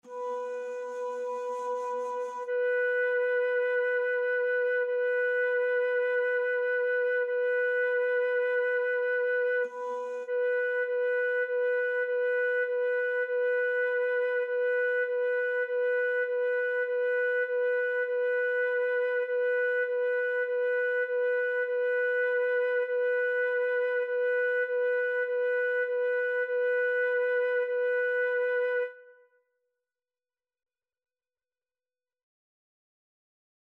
4/4 (View more 4/4 Music)
B5-B5
Instrument:
Flute  (View more Beginners Flute Music)
Classical (View more Classical Flute Music)